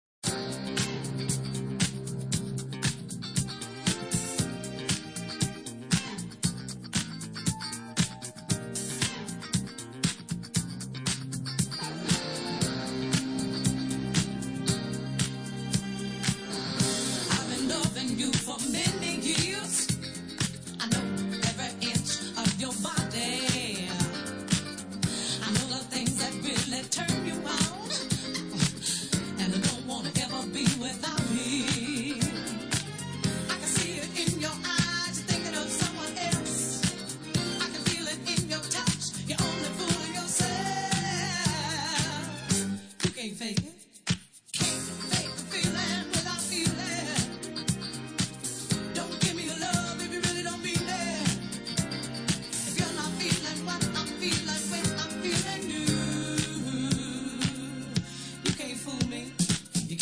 Loft classic that never gets tired.